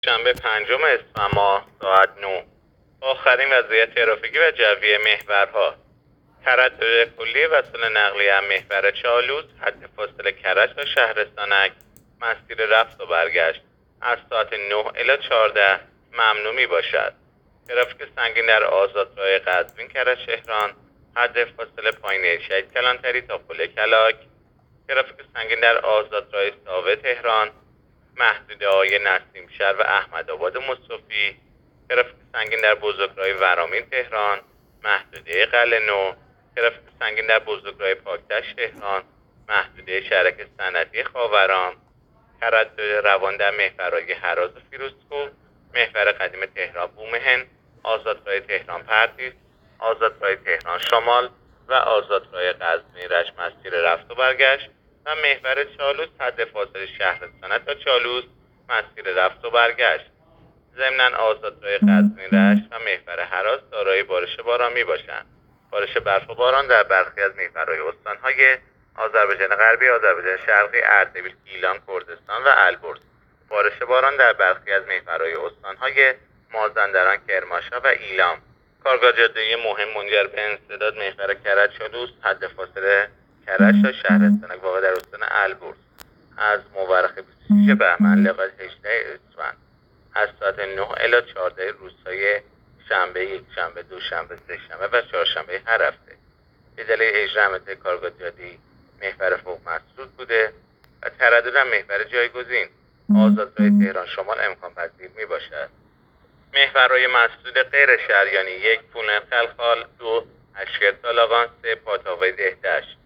گزارش رادیو اینترنتی از آخرین وضعیت ترافیکی جاده‌ها ساعت ۹ پنجم اسفند؛